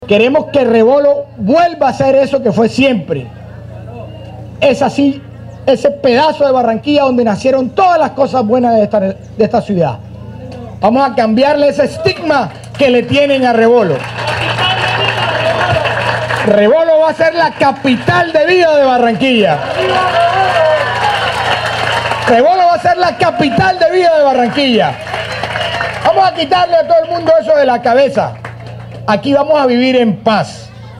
Durante la firma del acta de inicio de obras de remodelación del parque Rebolo, en la localidad Suroriente, El alcalde Alejandro Char exigió a toda la ciudadanía respetar la vida de los demás.
El pronunciamiento lo hizo el mandatario en el lanzamiento de la campaña ‘Yo Respeto a la Vida’, que tiene como fin, preservar el valor preciado de la vida, considerando que mayo es el mes de las madres y que históricamente ha sido uno de los más violentos.